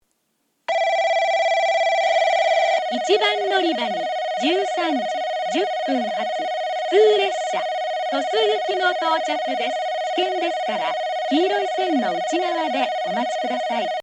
1番のりば接近放送（普通　鳥栖行き）
放送はJACROS簡易詳細型です。
スピーカーは0，1がカンノボックス型、2，3がカンノや円型ワイドホーン、TOAラッパ型です。